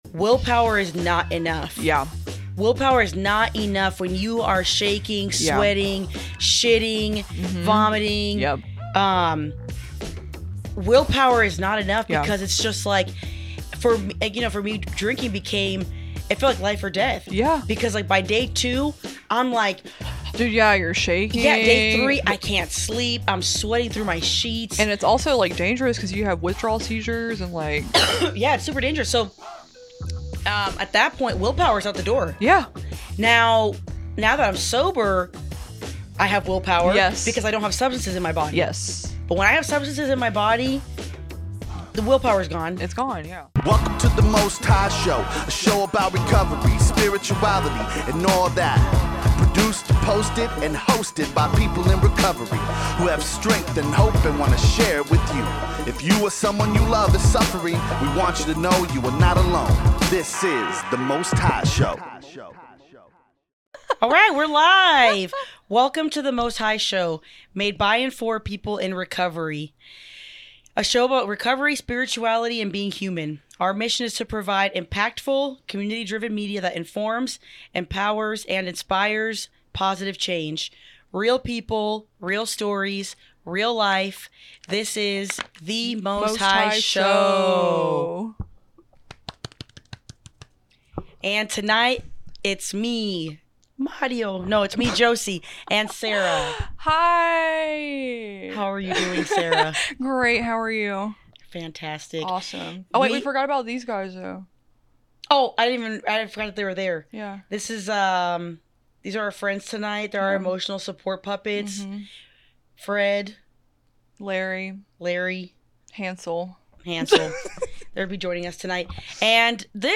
Just honesty, laughter, and truth from women who’ve lived it.